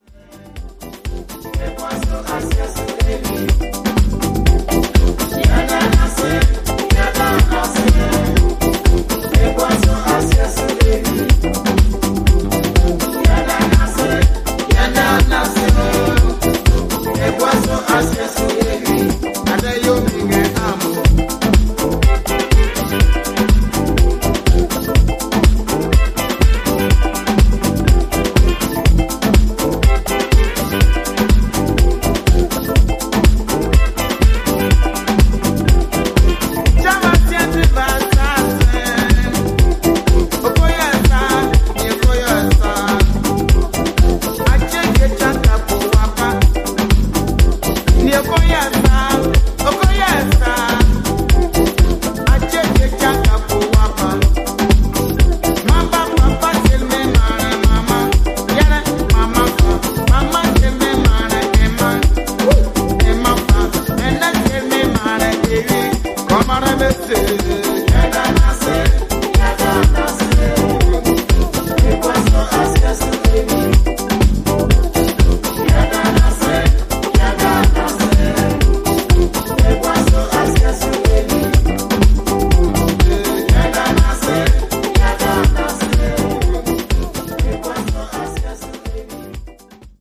アフリカン・ブギー、ハイライフ、ズーク路線の楽曲をDJユースに捌いたディスコハウスを全4曲を披露しています！